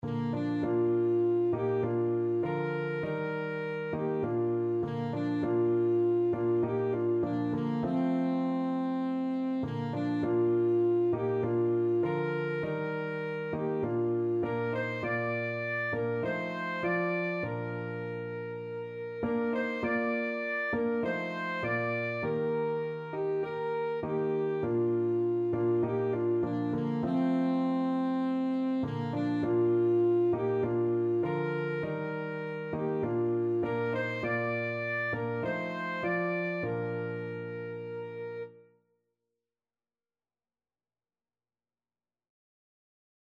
Christian
Alto Saxophone
4/4 (View more 4/4 Music)